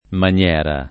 maniera [ man L$ ra ]